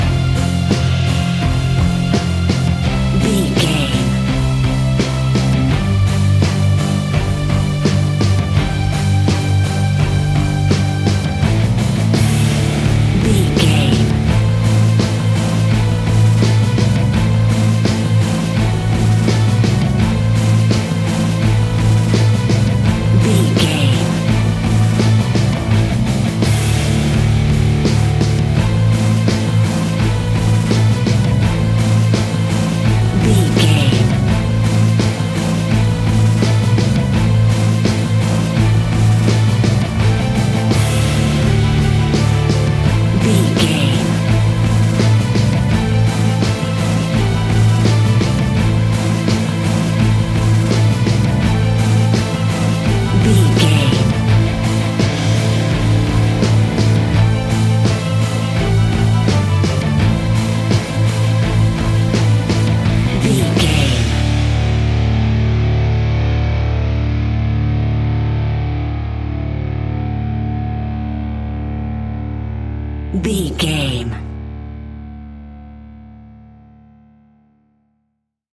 Epic / Action
Aeolian/Minor
Slow
metal
hard rock
guitars
horror rock
instrumentals
Heavy Metal Guitars
Metal Drums
Heavy Bass Guitars